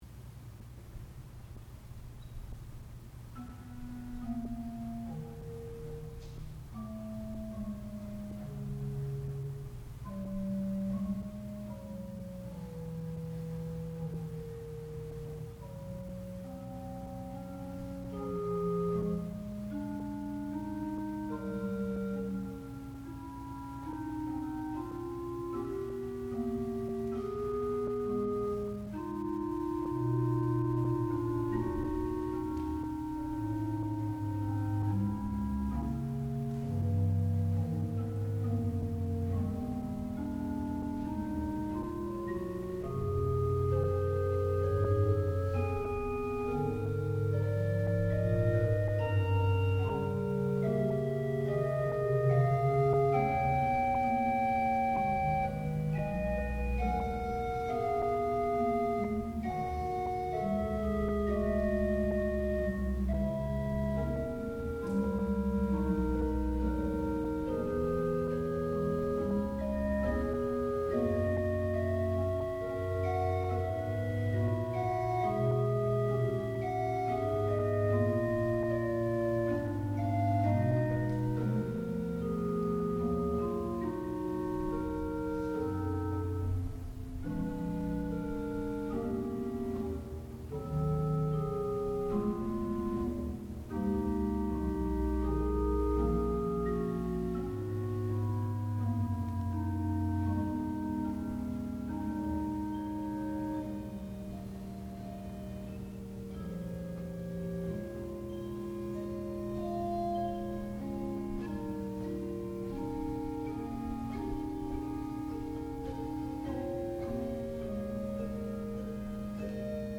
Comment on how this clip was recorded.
Graduate Recital